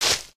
sounds / material / human / step / grass02.ogg
grass02.ogg